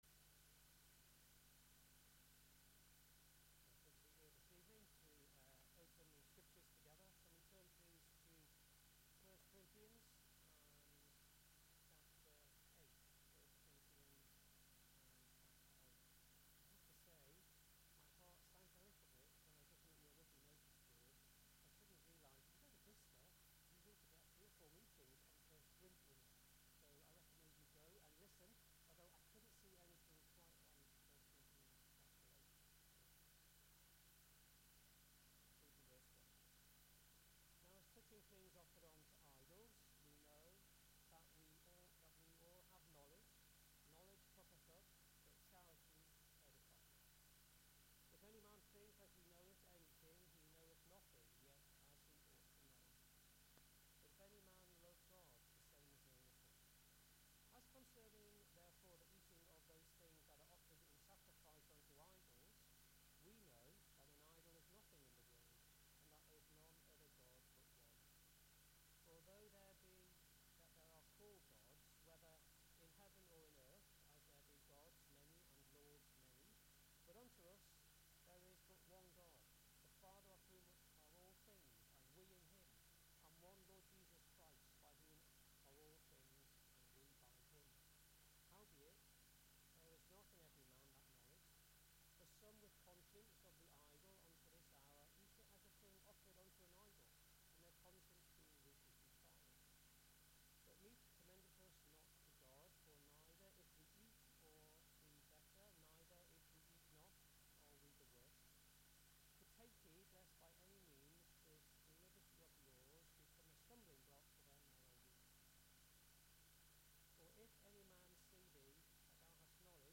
Service Type: Ministry